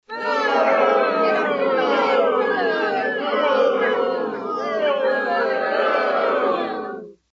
SFX – AUDIENCE BOO – A
SFX-AUDIENCE-BOO-A.mp3